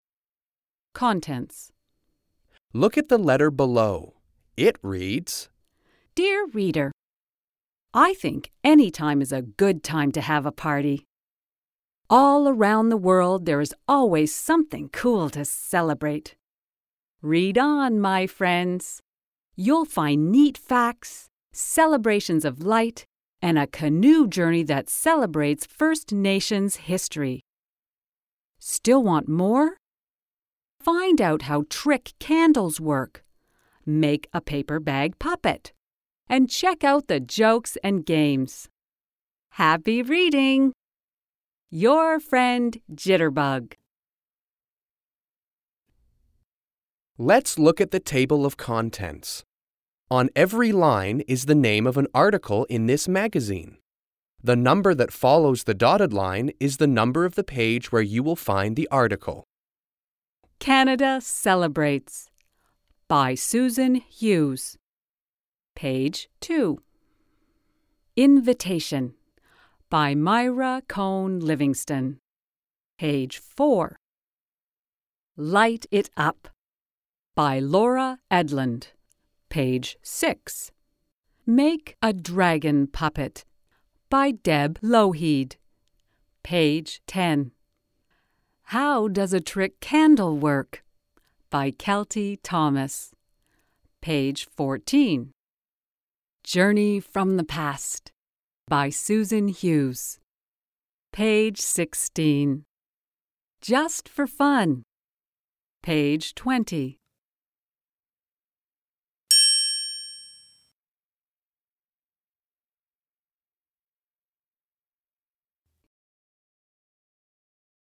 Fluent Readings